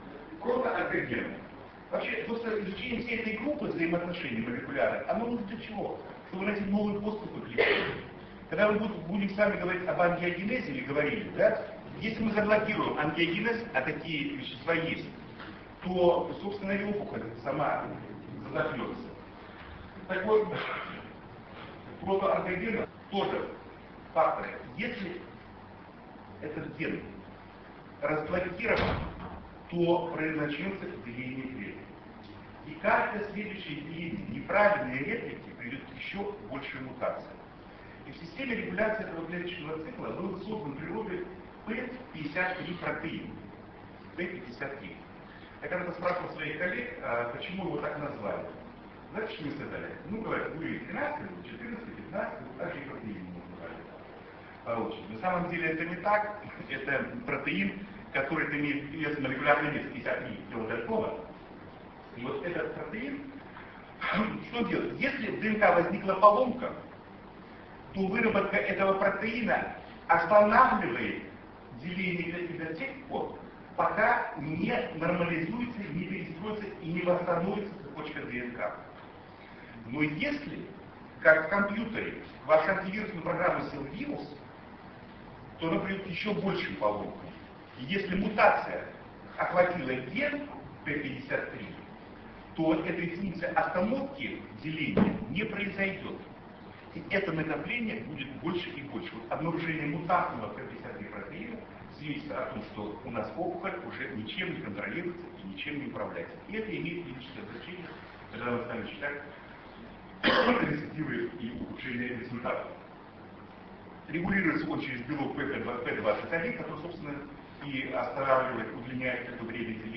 Съезд Ассоциации Урологов Дона с международным участием. Ростов-на-Дону, 27-28 октября 2004 года.
Лекция: "Прогностические факторы при раке предстательной железы".